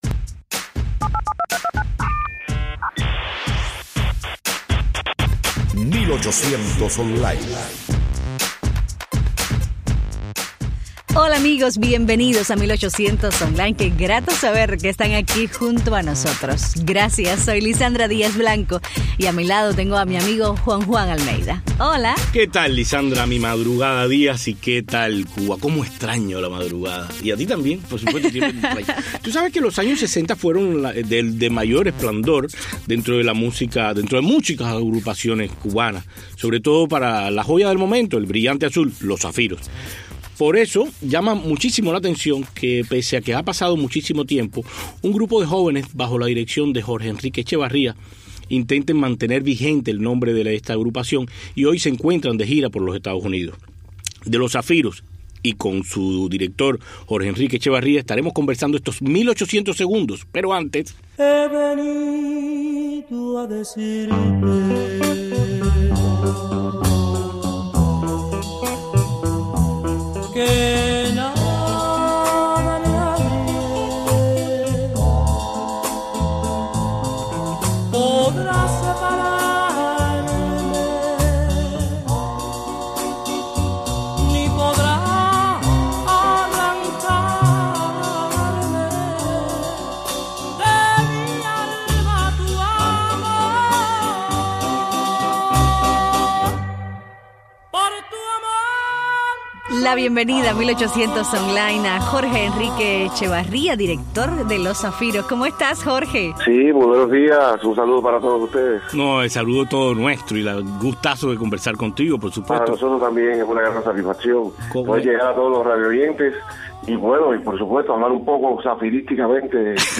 El cuarteto vocal Los Zafiros, continuadores de la legendaria agrupación del mismo nombre fundada en 1962, comenzó esta semana una gira por varias ciudades de Estados Unidos. Su director musical